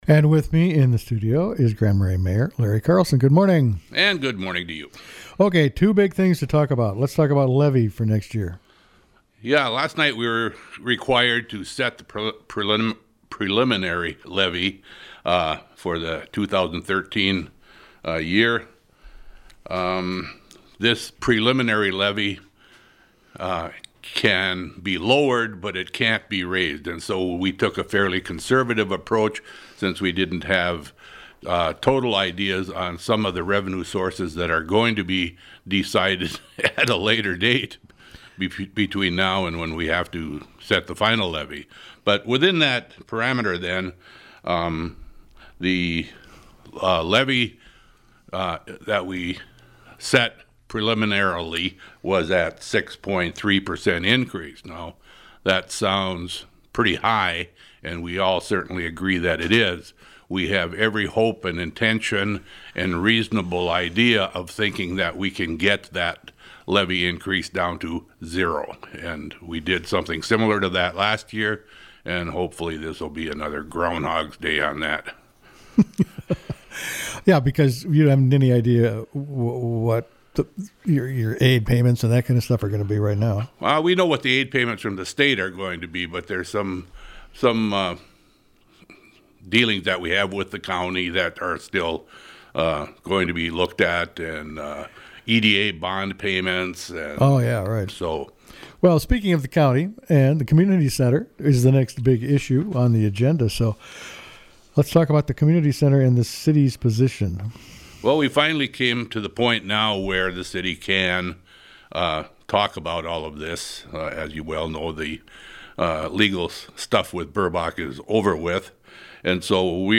spoke with Mayor Larry Carlson